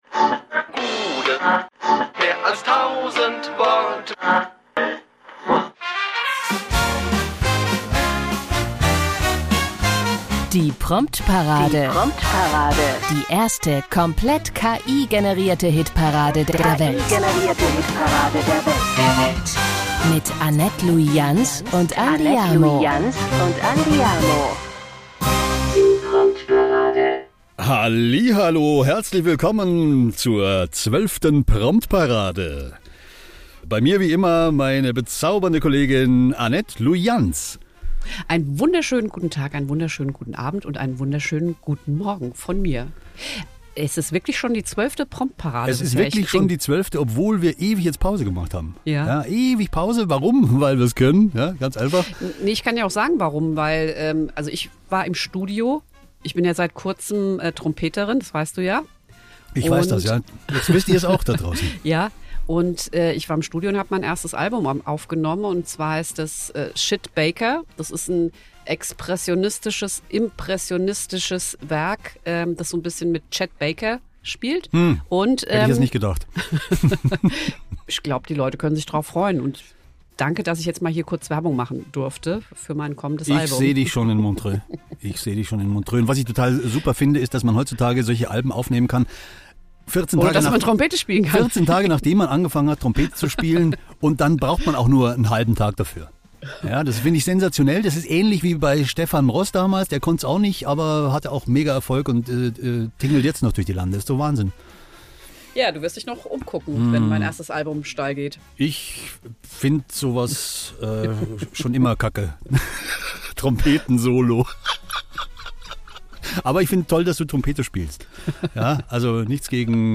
Und es ist tatsächlich schon wieder passiert: Die weltweit erste KI-Hitparade hat sich erneut selbst übertroffen. Wie immer stammt alles aus dem digitalen Hirn unserer Lieblingsmaschinen.